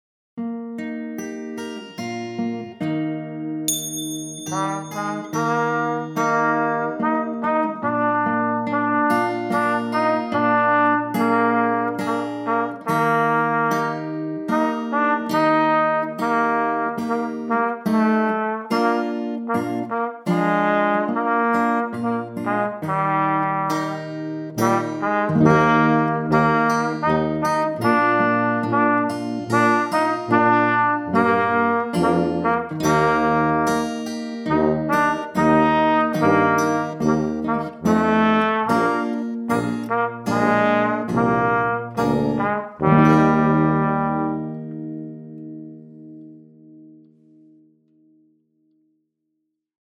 zum Mitspielen.